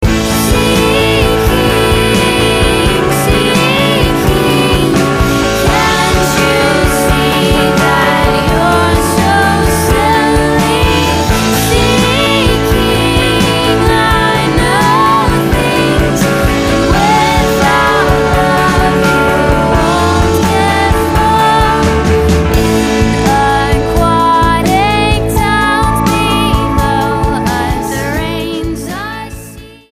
STYLE: Pop
adds a little atmosphere to the melody